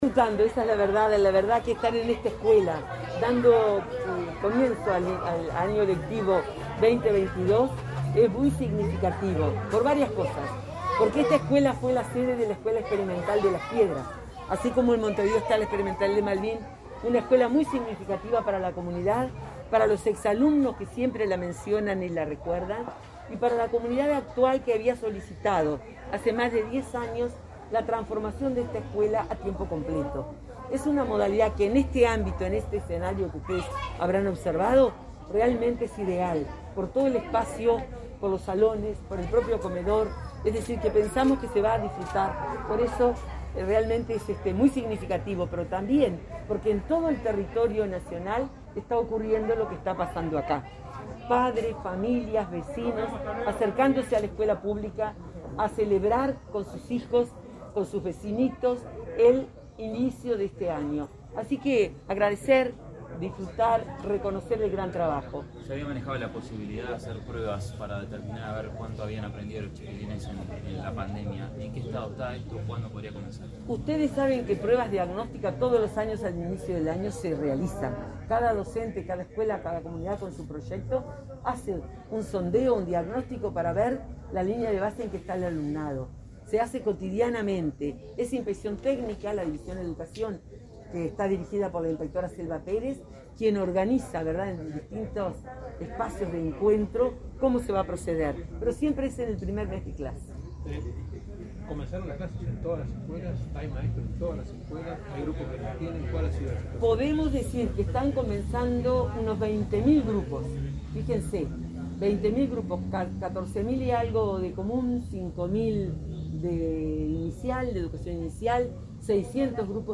Declaraciones a la prensa de la directora de Educación Inicial y Primaria, Graciela Fabeyro